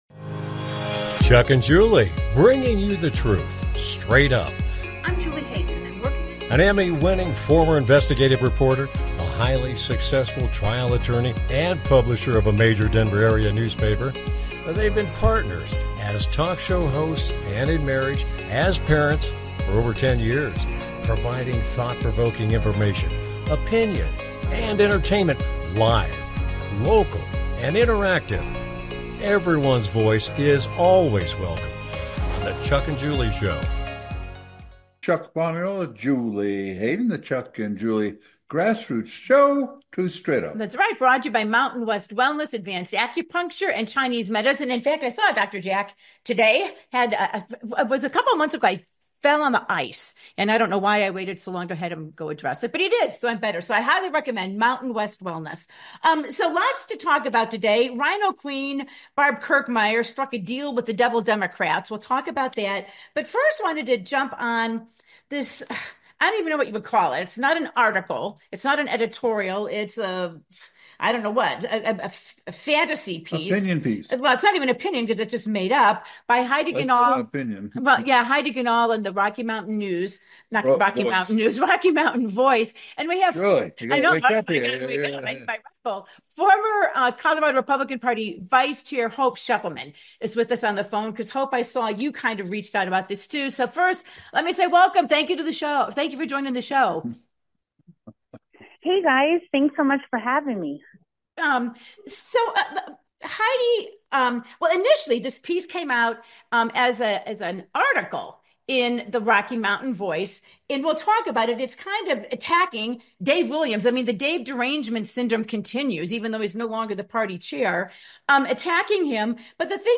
Talk Show Episode
Their program is a live Internet call-in talk show providing thought provoking information, conversation and entertainment.
If you want the truth straight up and enjoy passionate debate this is the show for you.